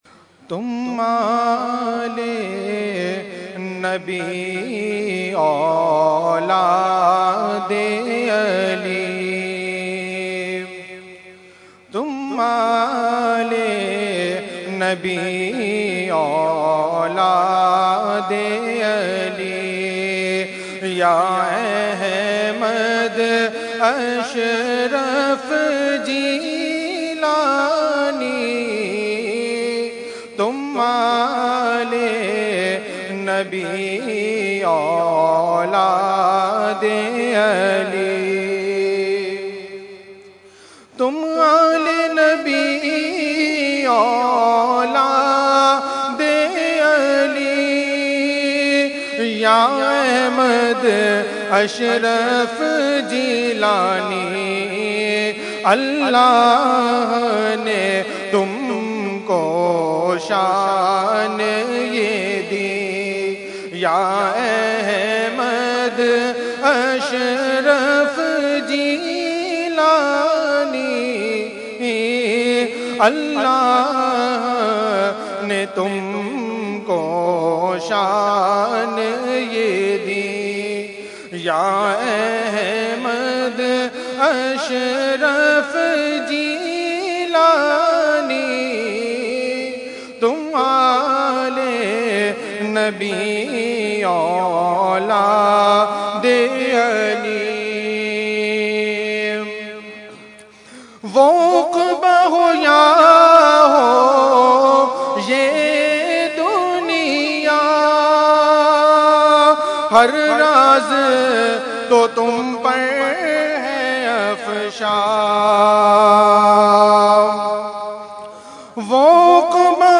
Category : Manqabat | Language : UrduEvent : Urs Ashraful Mashaikh 2015